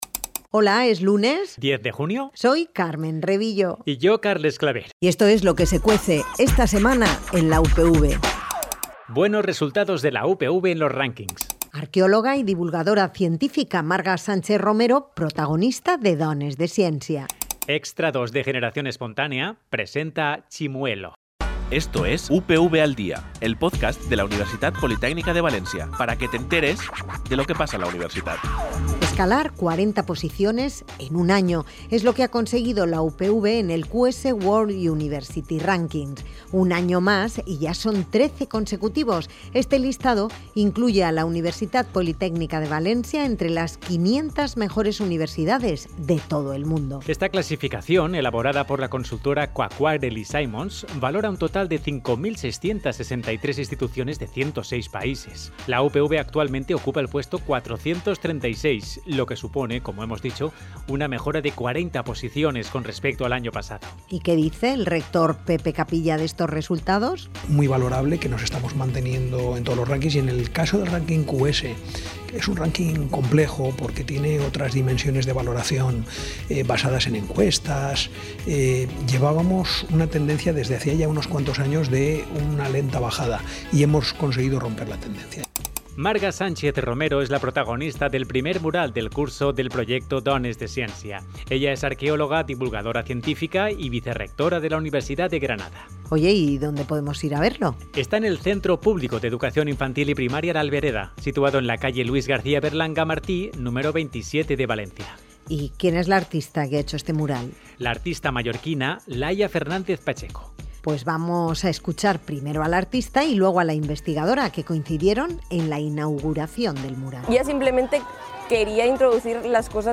Informativos